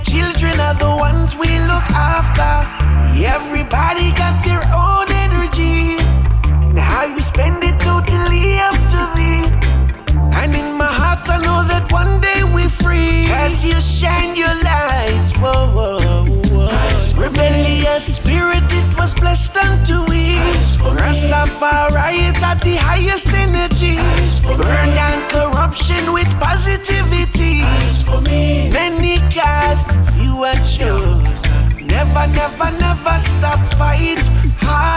Жанр: Регги